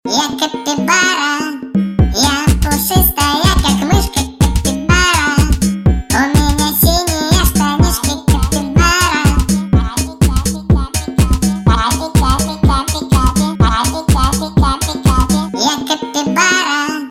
Remix Electronic веселые